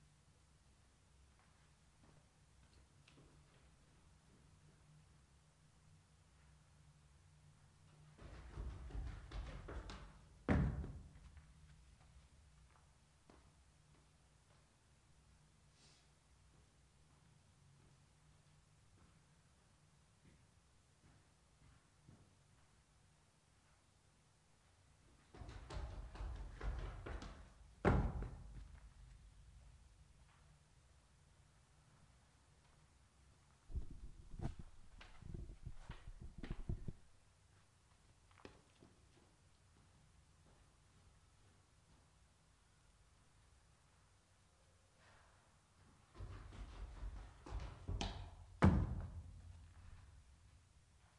上楼梯时要注意麦克风处理噪音
描述：跑上楼梯也许这里和那里有一个低矮的架子会有帮助绝对是一个可用的录音不错的感觉就个人而言，我在顶部非常累有5层楼在前几层楼的麦克风处理不好（删除）
标签： 运行 楼梯 最多
声道立体声